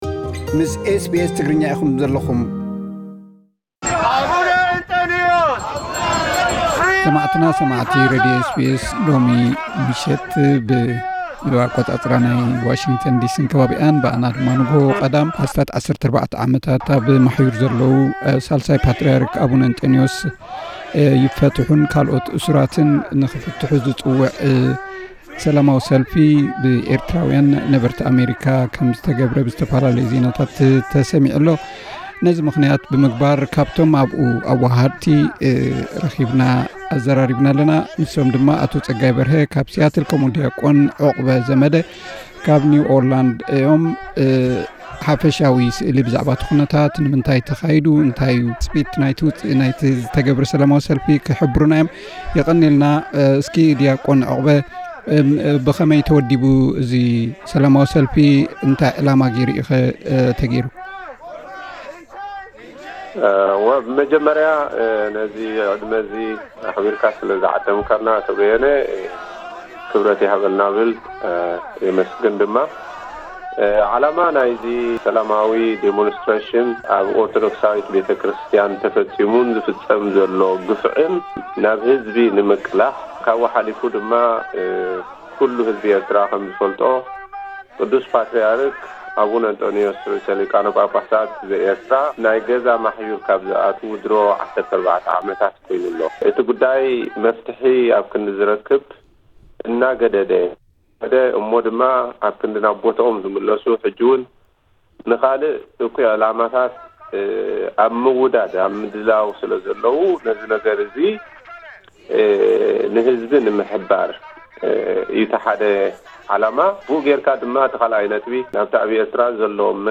ዝርርብ